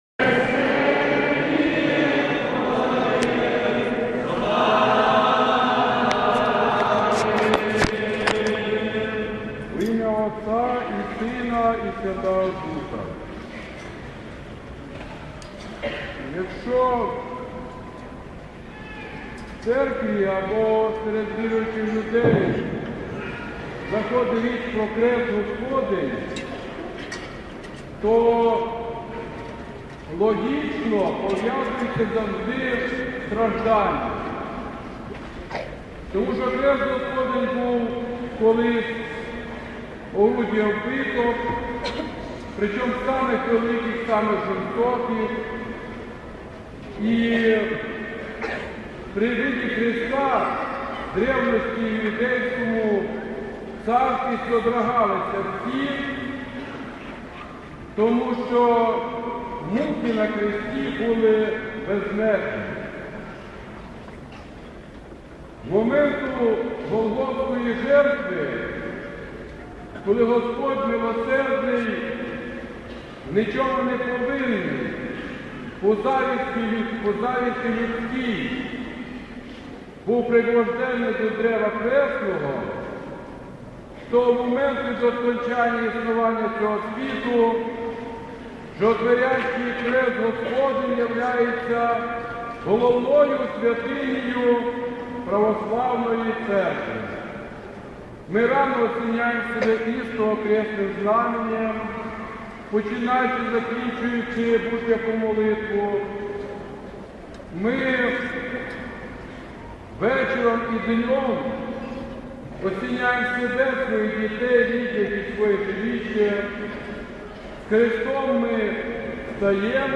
Проповідь у Неділю 16-ту після П’ятидесятниці – Храм Святителя Іоанна Шанхайського і Сан-Франциського м. Ужгорода